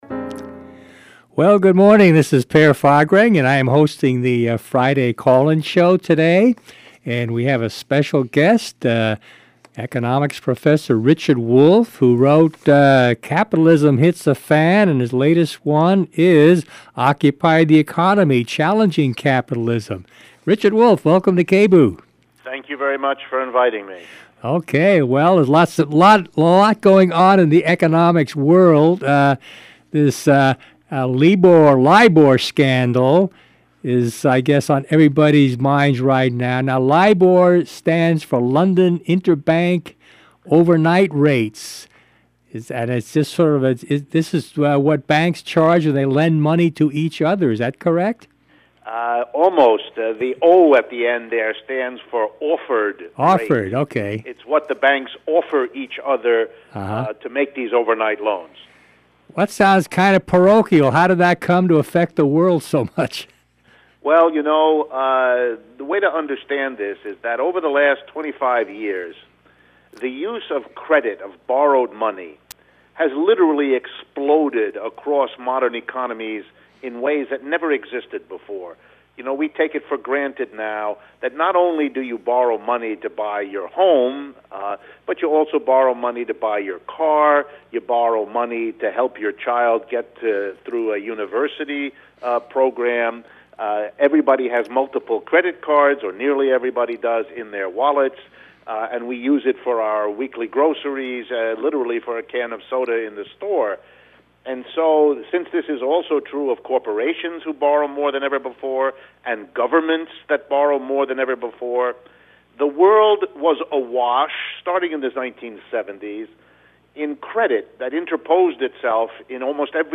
Play Rate Listened List Bookmark Get this podcast via API From The Podcast KBOO Radio is a community-powered station in Portland Oregon Join Podchaser to...